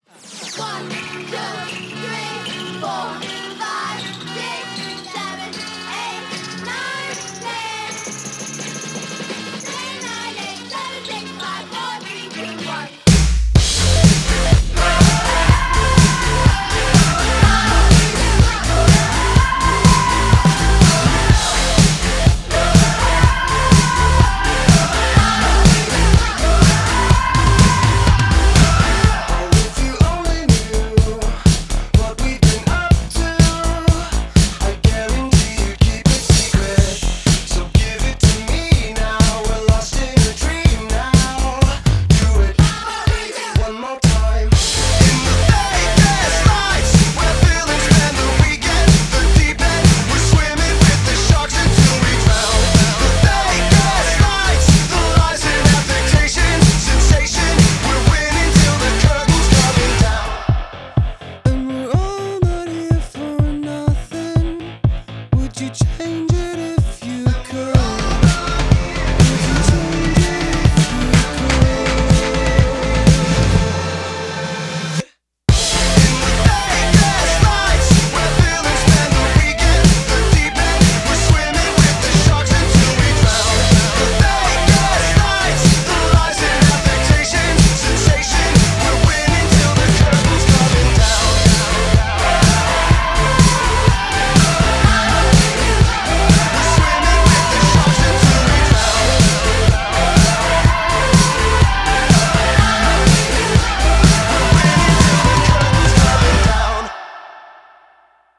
BPM124